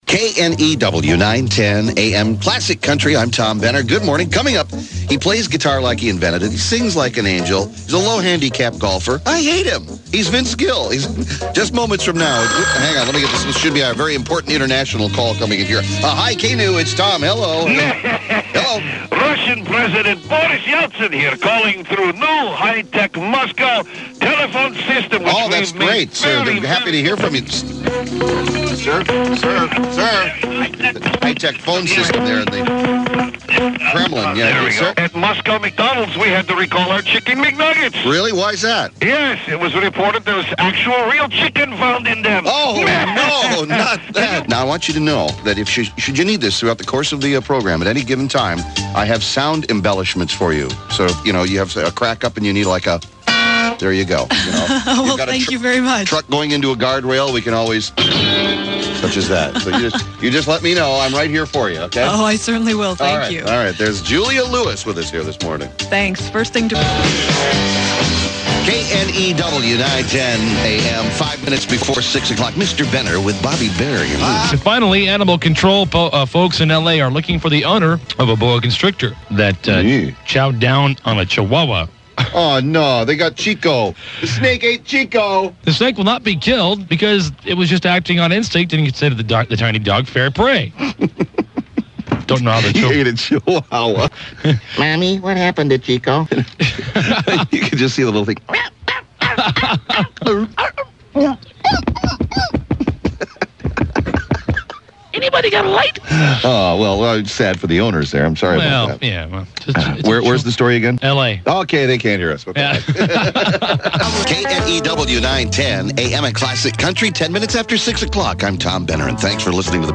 KNEW Aircheck